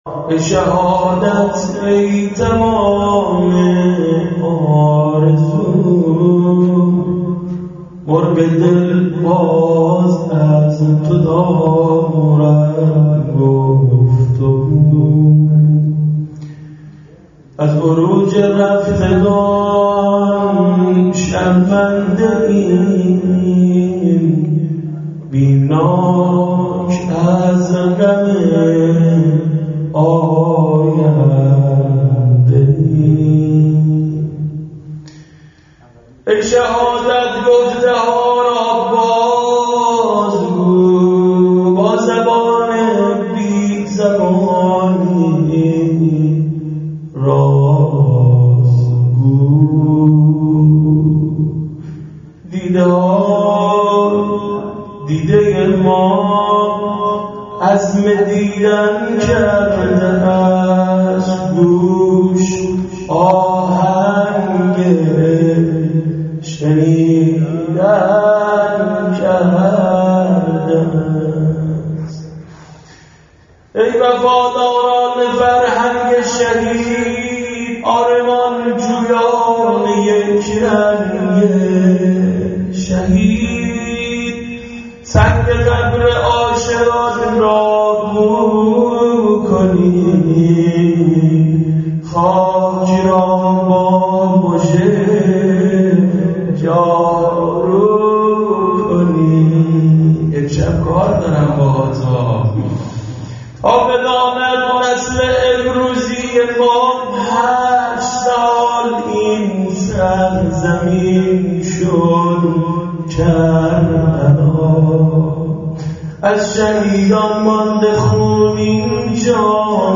ای-شهادت-ای-تمام-آرزو-شعر-خوانی-شهدا.mp3